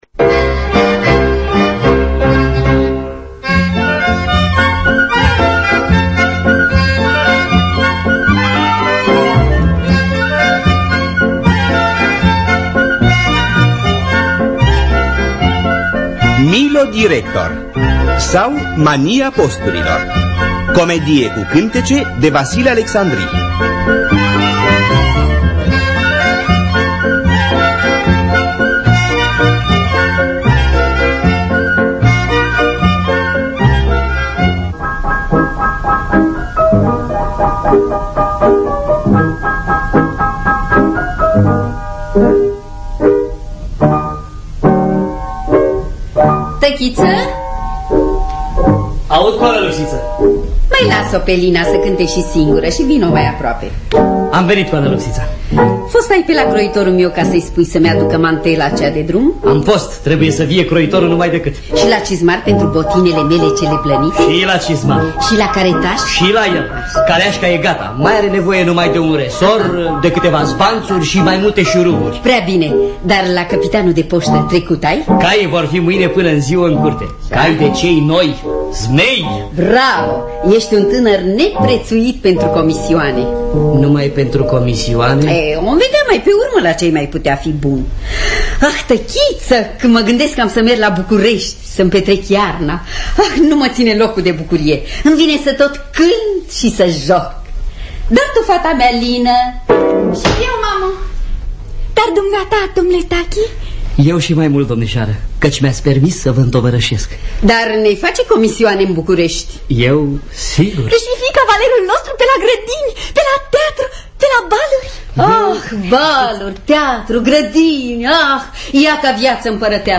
Millo director sau Mania posturilor de Vasile Alecsandri – Teatru Radiofonic Online